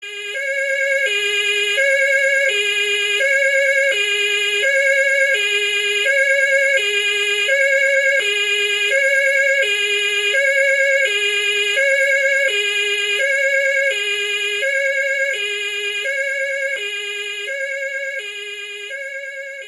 Klingelton Feuerwehr
Kategorien Soundeffekte